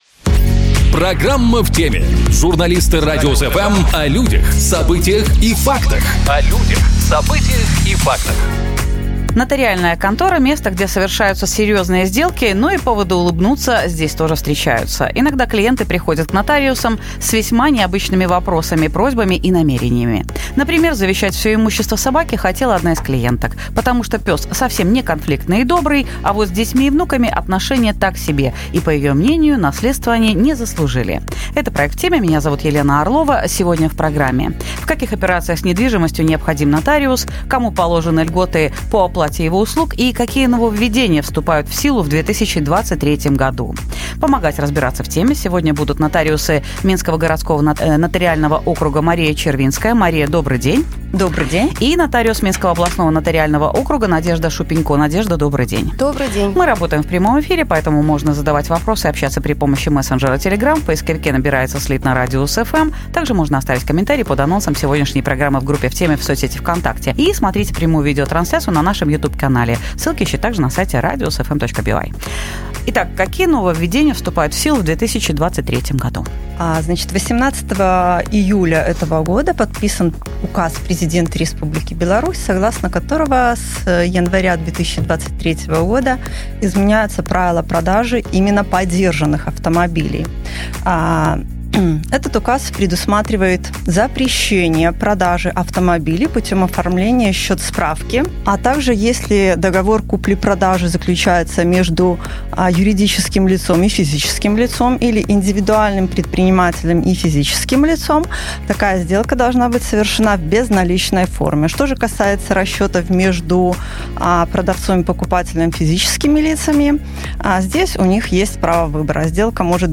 Прямая линия с нотариусами